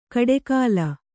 ♪ kaḍekāla